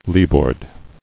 [lee·board]